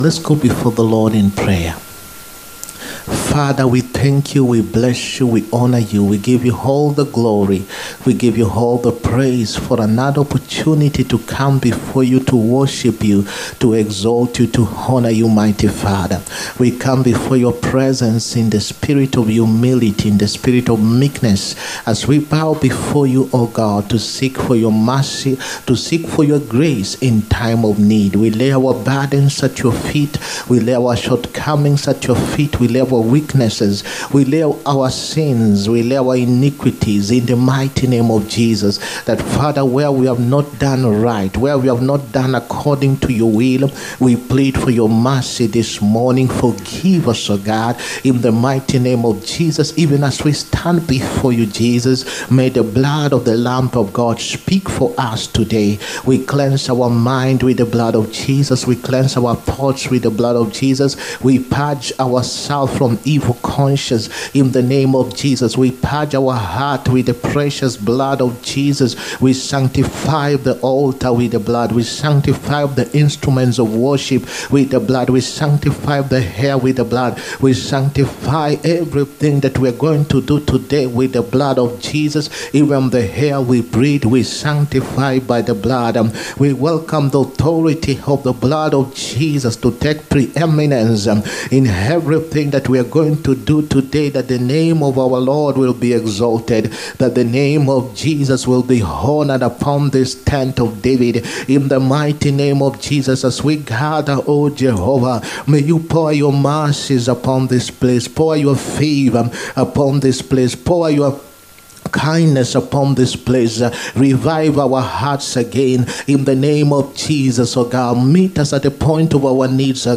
SUNDAY WORSHIP SERVICE. THERE IS RECRUITMENT IN HEAVEN. 16TH FEBRUARY 2025.